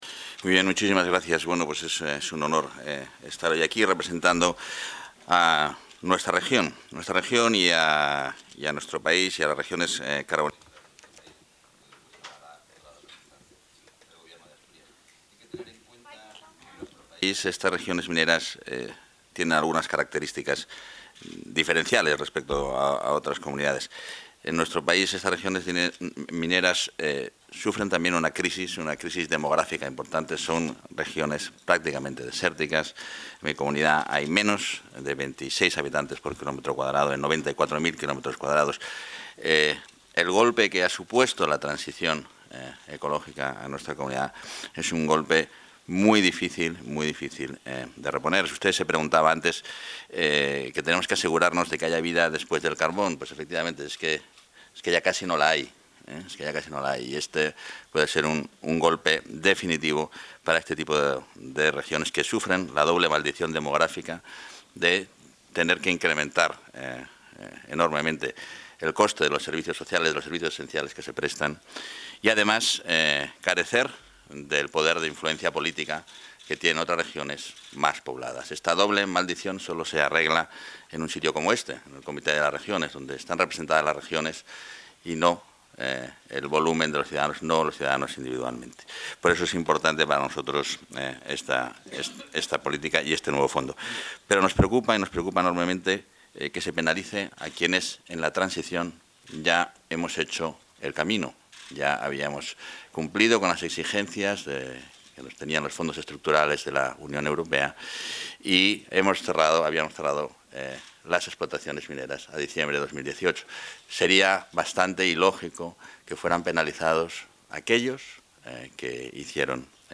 Intervención.